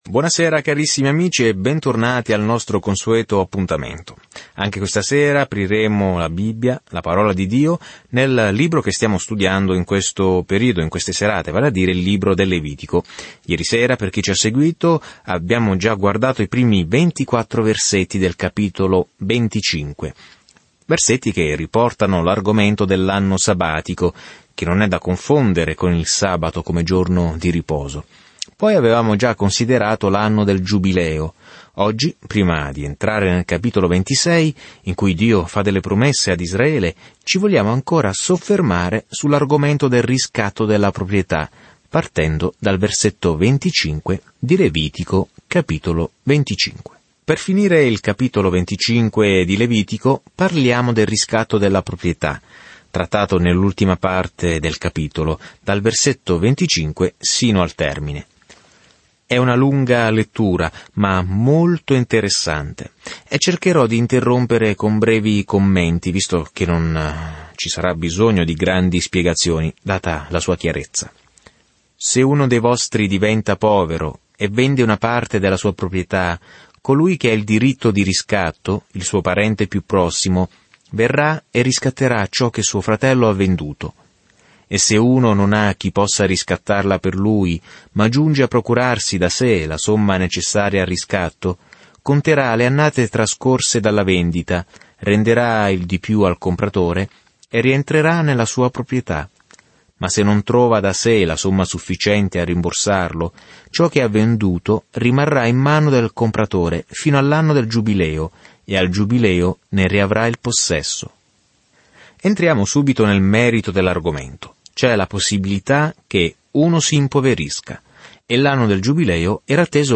Nell'adorazione, nel sacrificio e nella riverenza, il Levitico risponde a questa domanda per l'antico Israele. Viaggia ogni giorno attraverso il Levitico mentre ascolti lo studio audio e leggi versetti selezionati della parola di Dio.